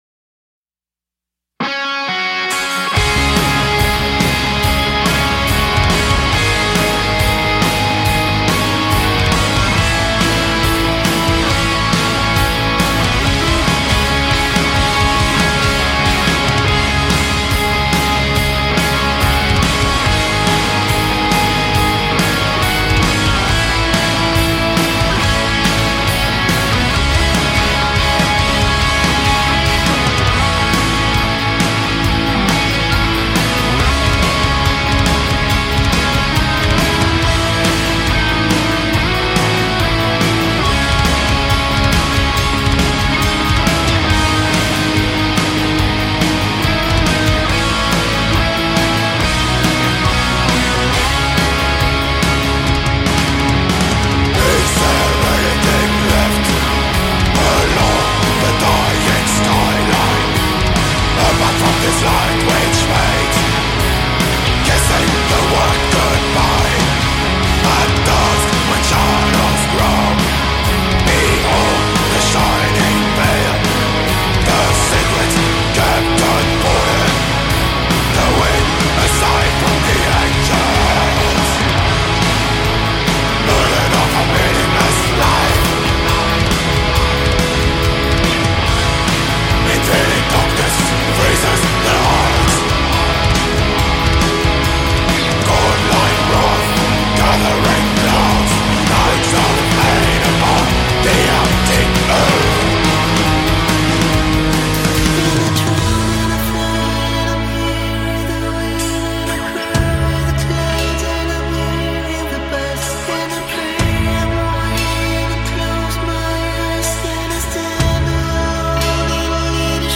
Gothic Metal